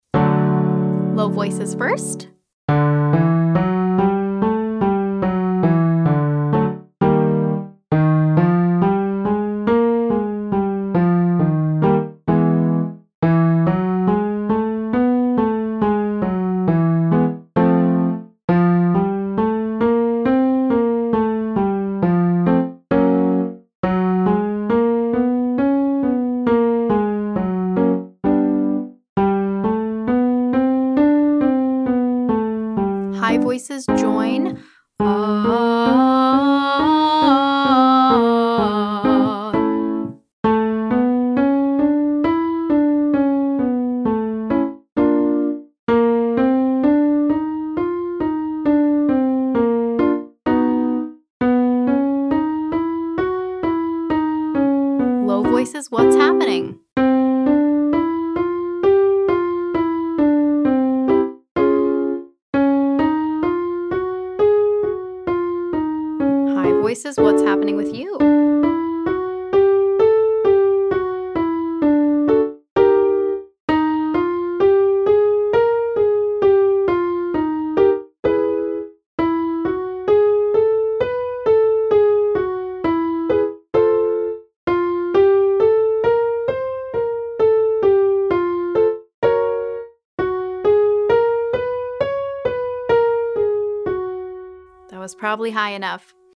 Exercises for day 4: